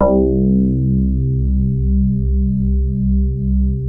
JAZZ MID  C1.wav